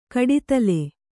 ♪ kaḍitale